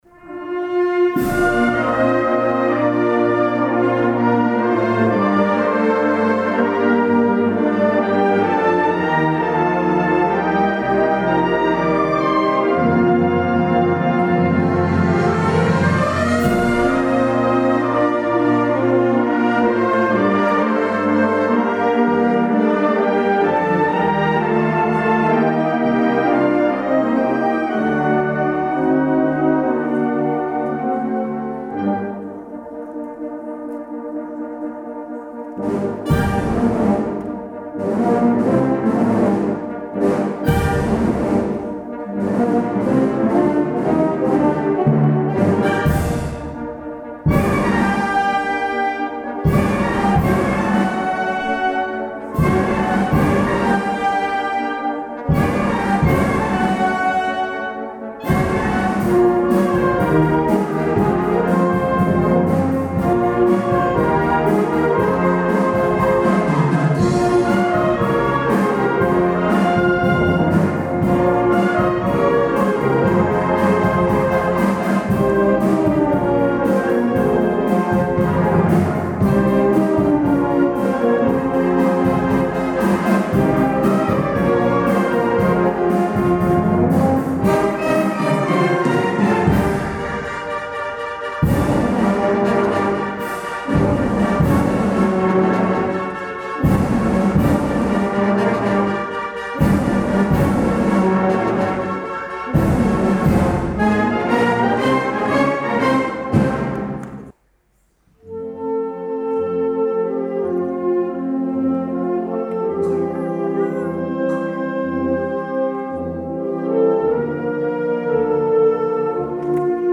juntament amb Banda de Música de Llucmajor